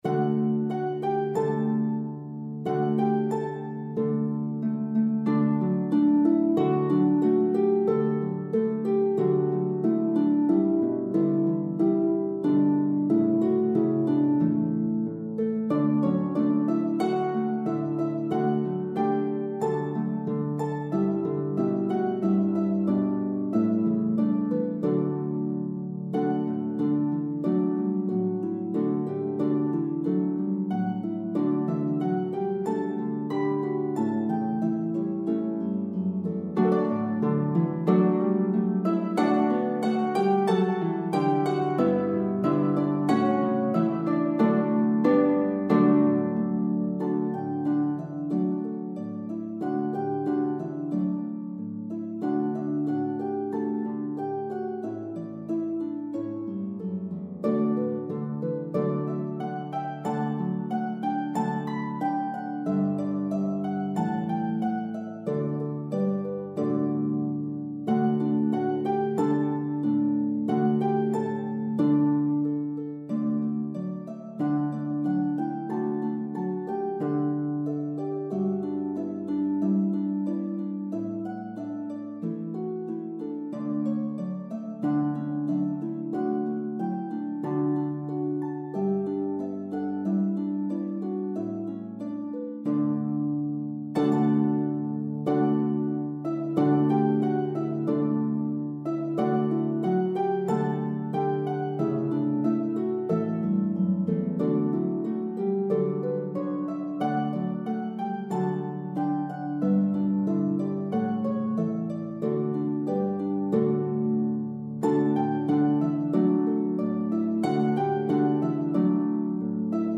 for lever harp duet or pedal harp duet
Conductor’s Score